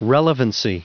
Prononciation du mot relevancy en anglais (fichier audio)
Prononciation du mot : relevancy